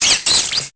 Cri de Togedemaru dans Pokémon Épée et Bouclier.